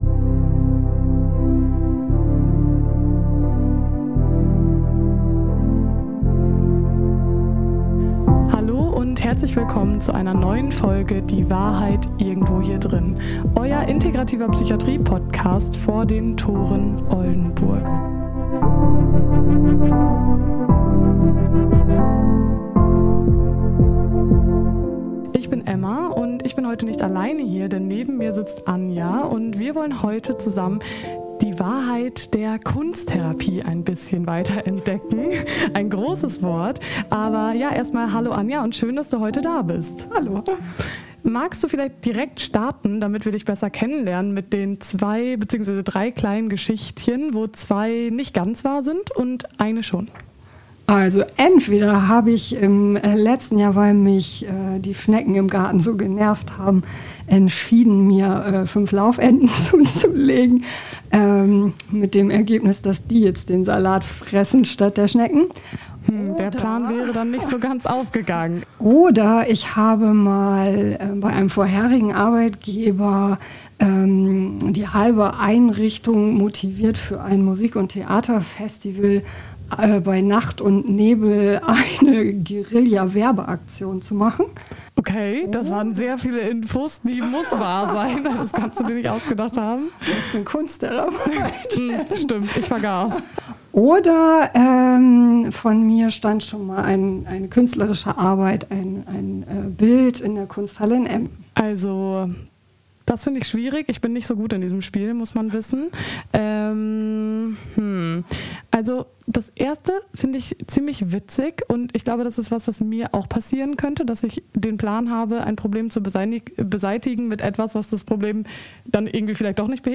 #32 KUNSTTHERAPIE Experten-Talk ~ Die Wahrheit Irgendwo Hier Drinnen Podcast